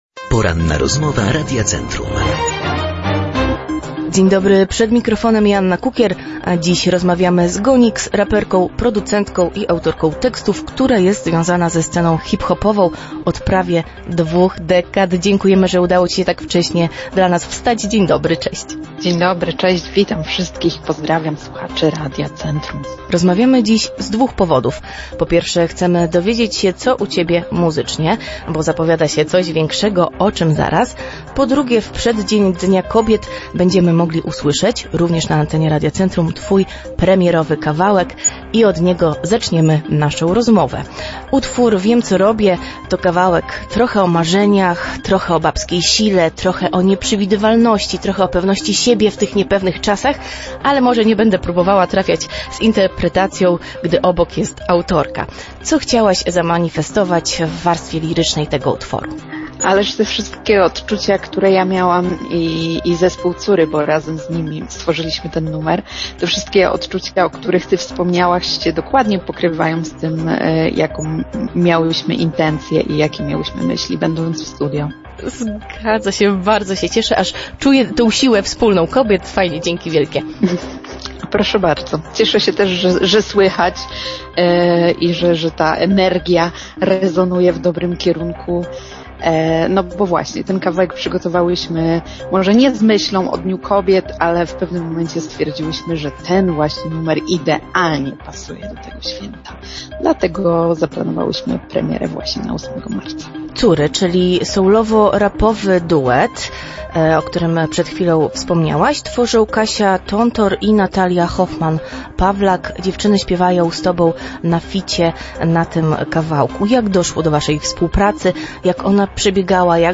Za nami Poranna Rozmowa Radia Centrum.
ROZMOWA-4-1.mp3